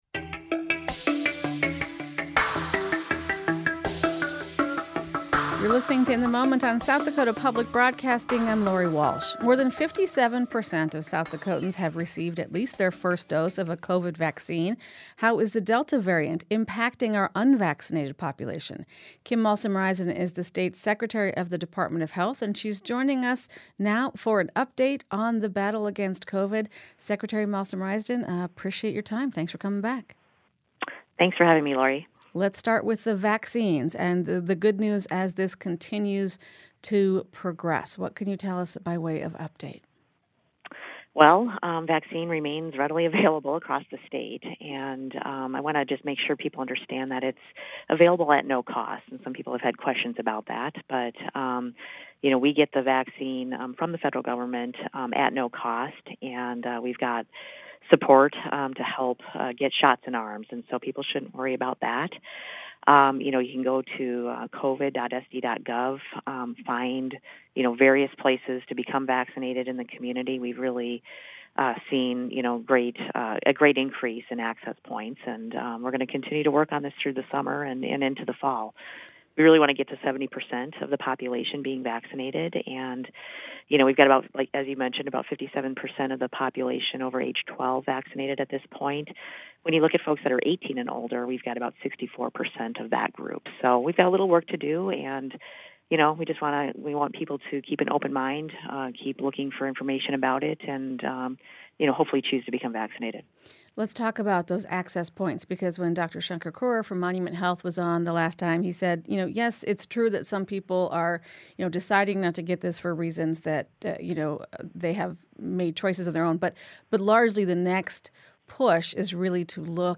South Dakota’s Secretary of Health Kim Malsam-Rysdon thinks it’s concerning.